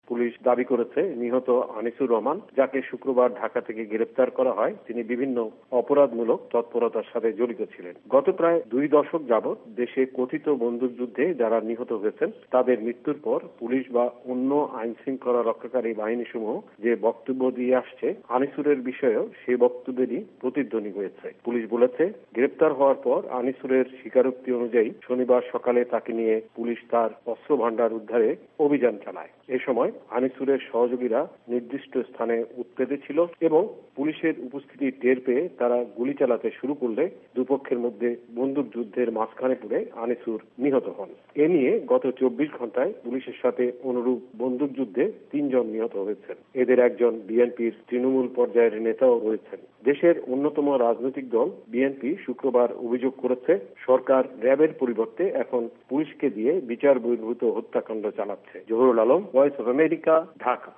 ভয়েস অফ এ্যামেরিকার ঢাকা সংবাদদাতাদের রিপোর্ট